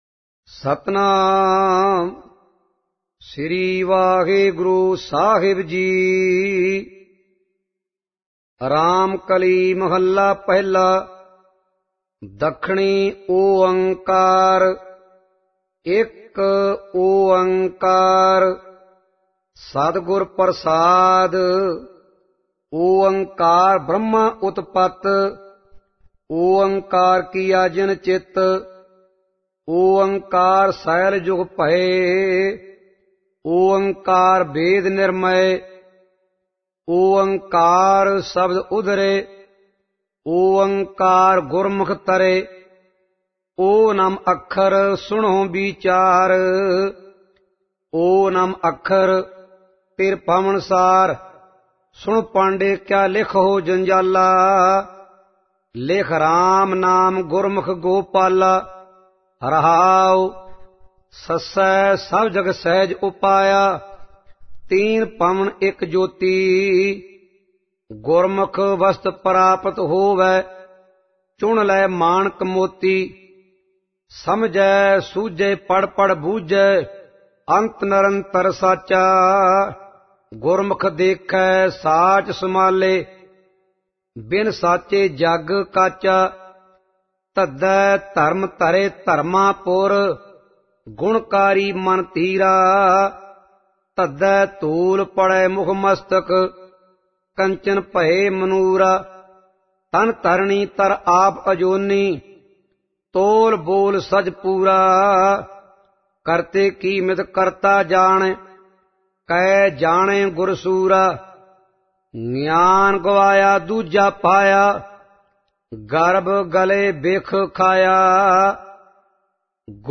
Album:Dakhni Onkar Genre: -Gurbani Ucharan Album Info